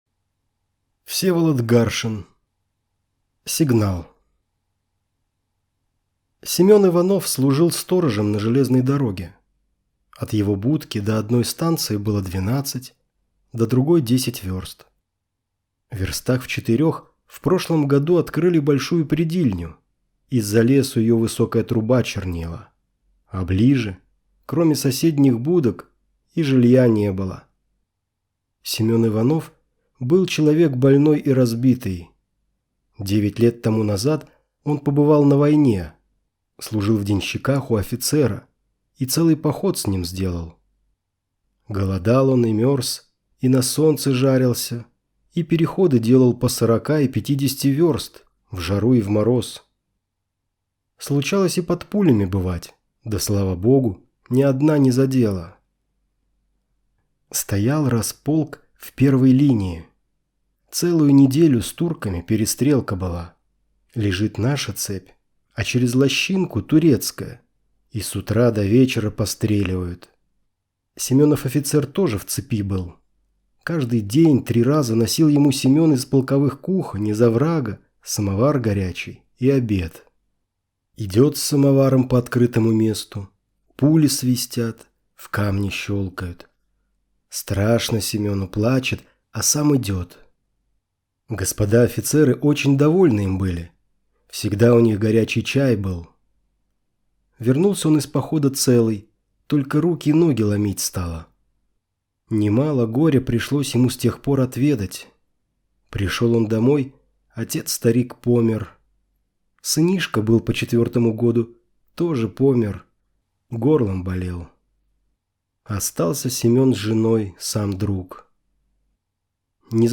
Сигнал - аудио рассказ Гаршина В.М. Рассказ Всеволода Гаршина о неравенстве между сословиями в царской России.